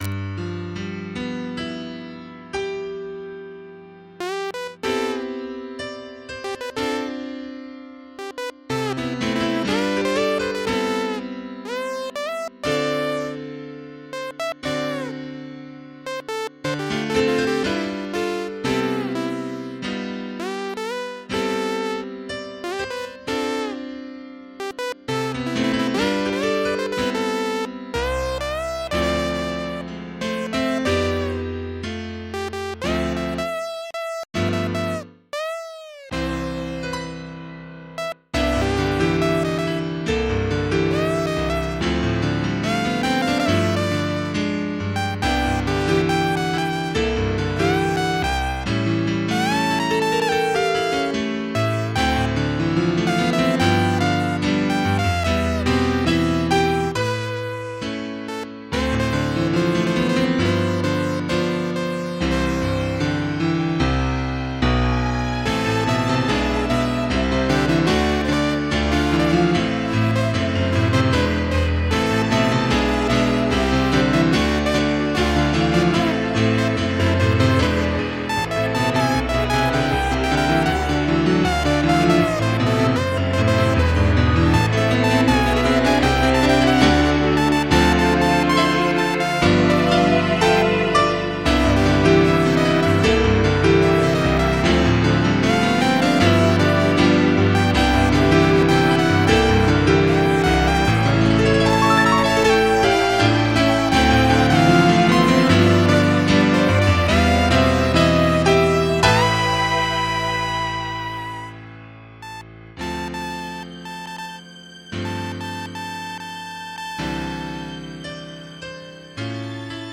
MP3 (Converted)